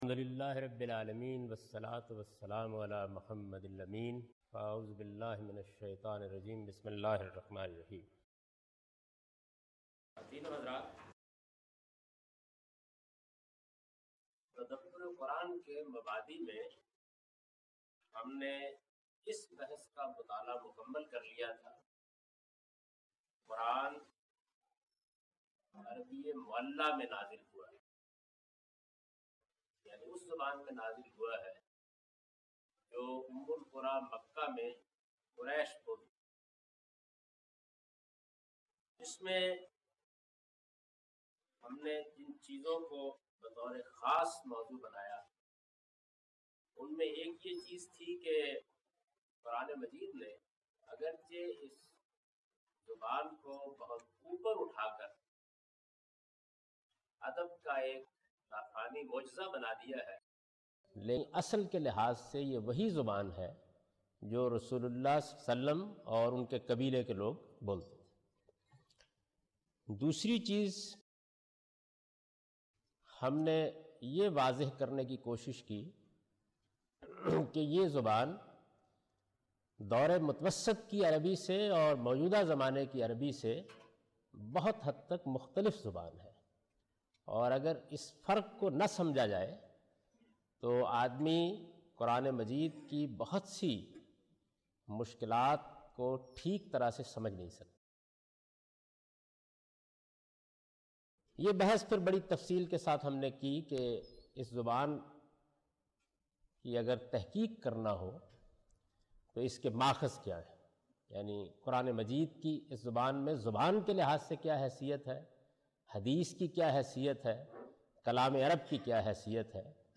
A comprehensive course on Islam, wherein Javed Ahmad Ghamidi teaches his book ‘Meezan’.
In this lecture he teaches the importance of appreciation of classical Arabic in order to truly understand Quran. (Lecture no.14 – Recorded on 28th February 2002)